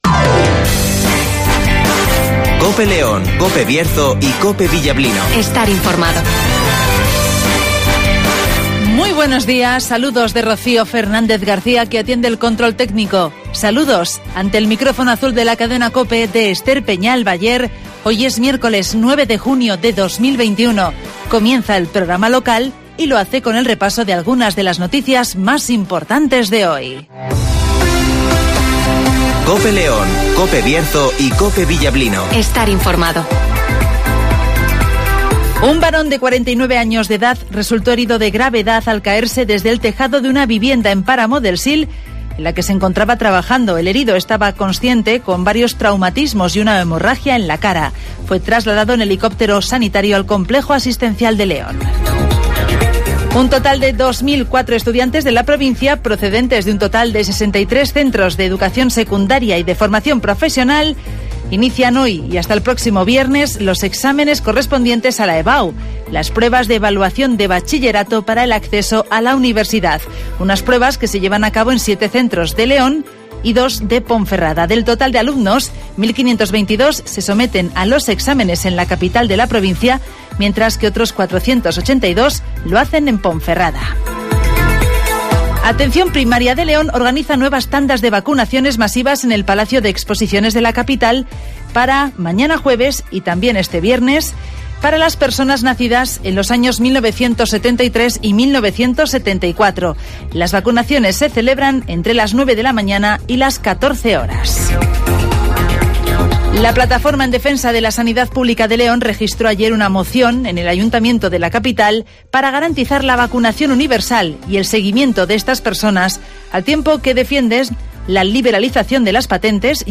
Avance informativo, El Tiempo (Neucasión) y Agenda (Carnicerías Lorpy)